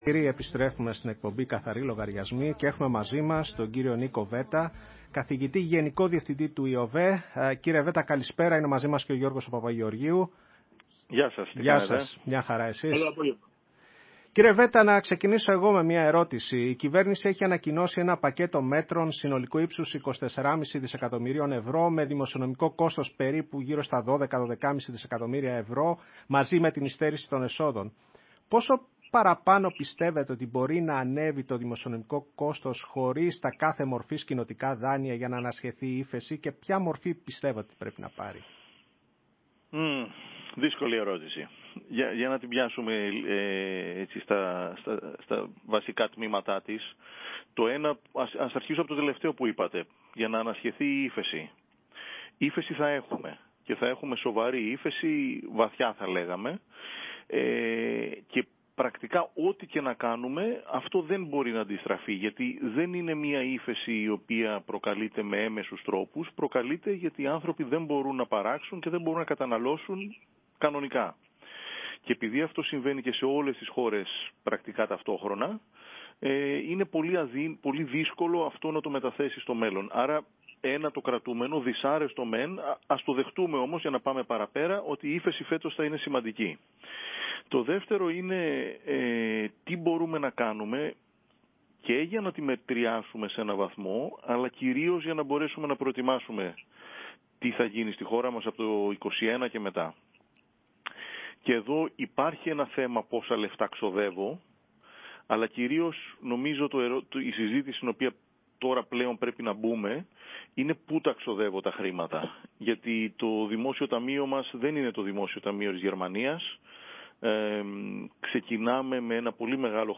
Συνέντευξη στο ραδιόφωνο της ΕΡΤ